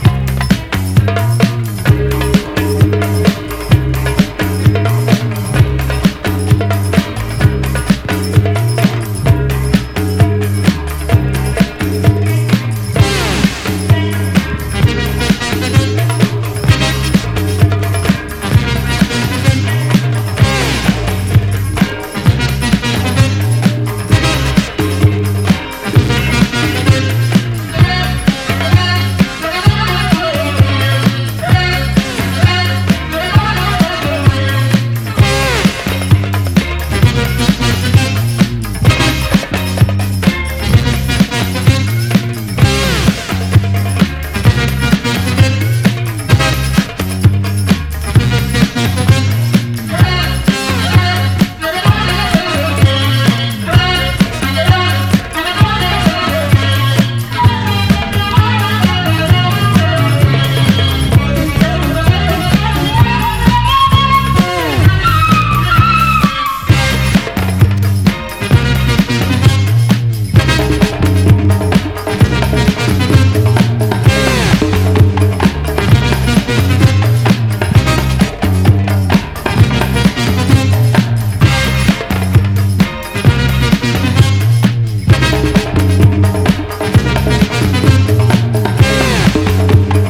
MODERN SOUL
EARLY80Sモダン・ソウル/ブギーの最高峰！